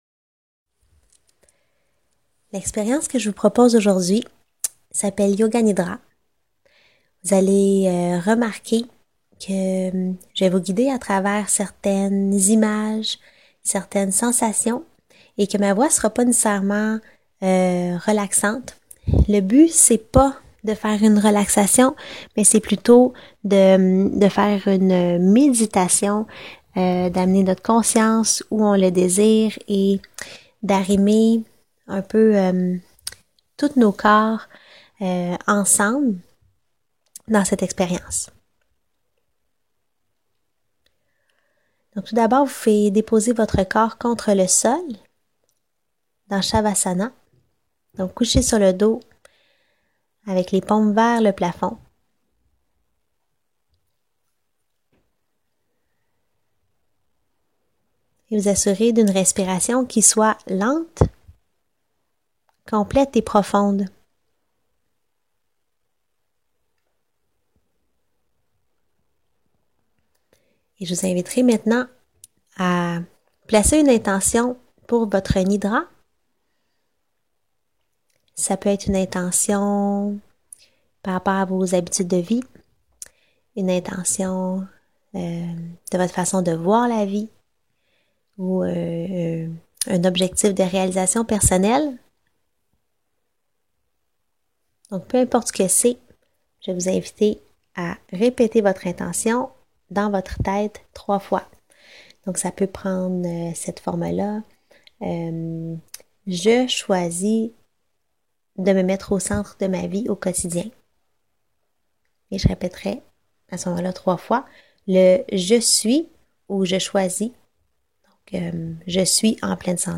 Yoga Nidra Gratuit BONUS